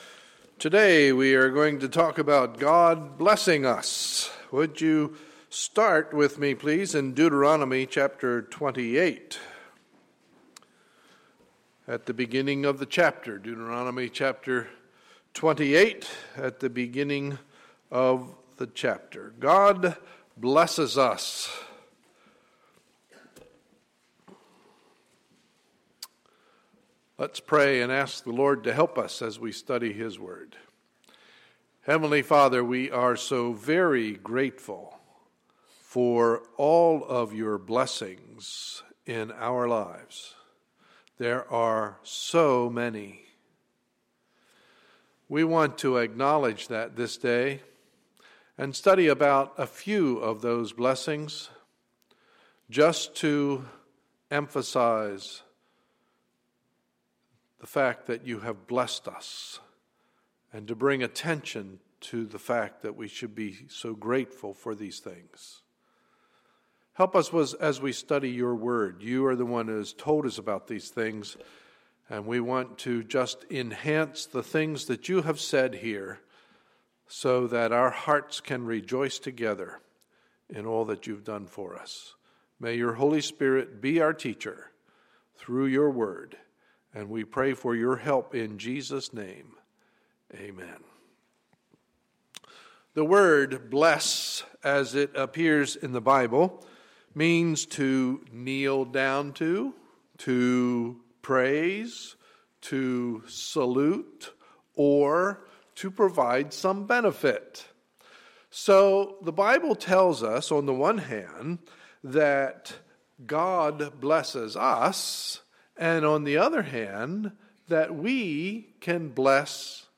Sunday, November 24, 2013 – Morning Service